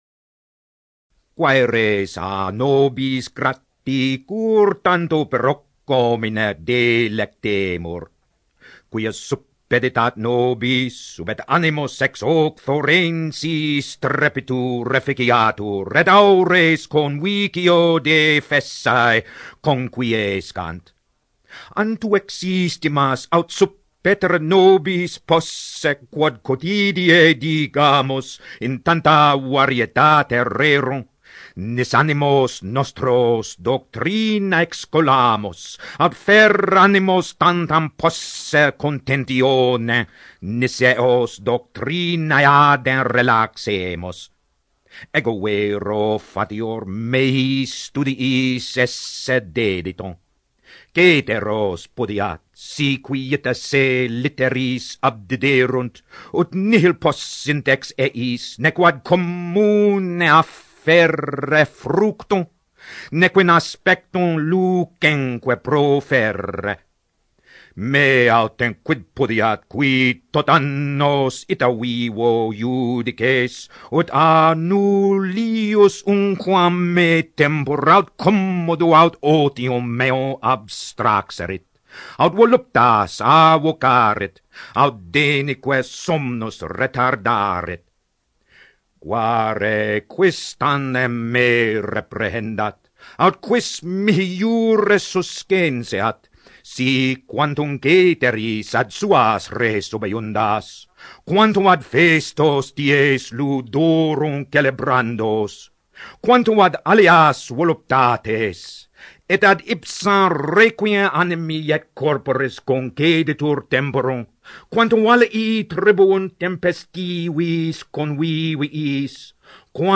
Recordings of Roman poetry and prose ready for your mp3 player